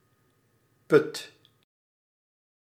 Ääntäminen
IPA: /ˈpʏt/